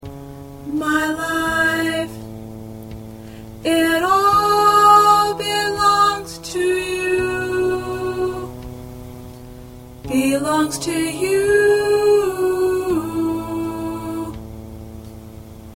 3 parts — Soprano, Alto, Tenor
Alto part preview